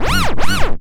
DUBL.SCREECH.wav